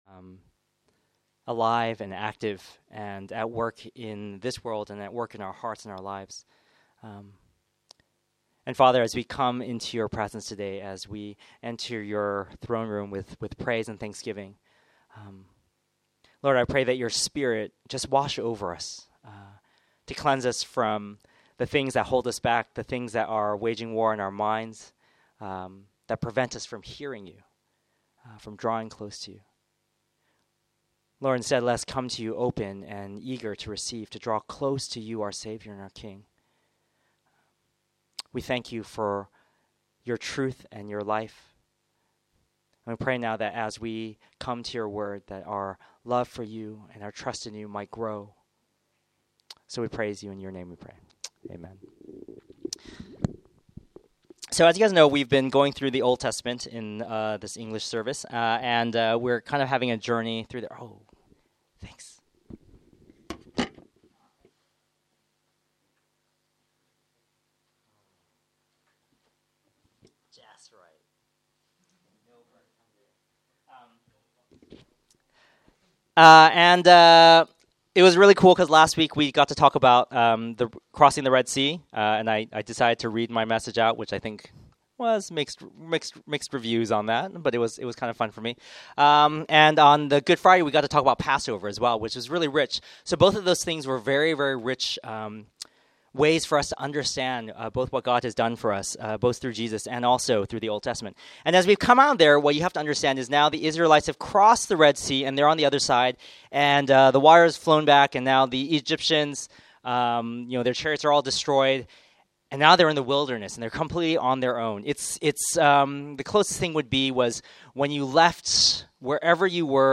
Category Sermon Audio